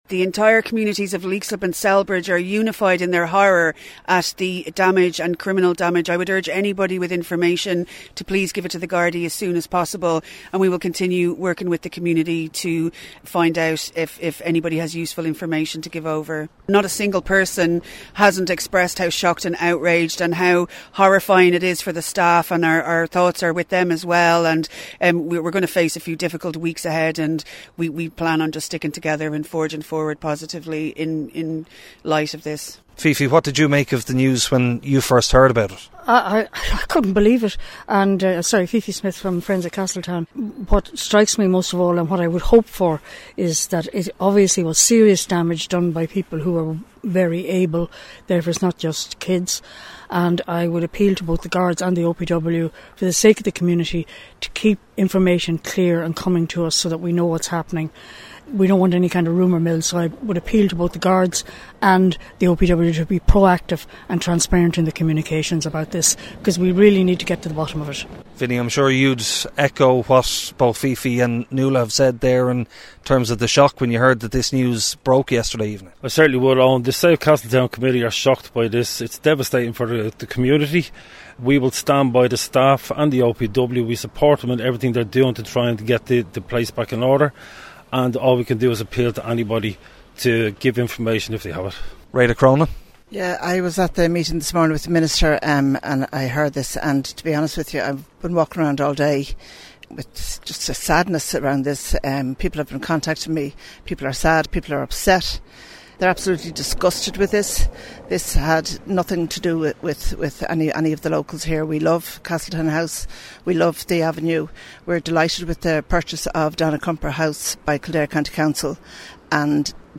Last night, Kfm spoke to members from a number of local groups, TDs and Cllrs who all described their shock over the latest development.